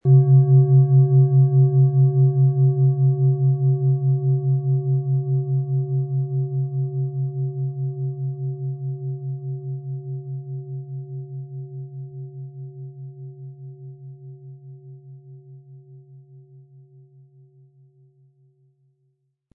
OM Ton
Sie sehen eine Planetenklangschale OM-Ton, die in alter Tradition aus Bronze von Hand getrieben worden ist.
Um den Original-Klang genau dieser Schale zu hören, lassen Sie bitte den hinterlegten Sound abspielen.
MaterialBronze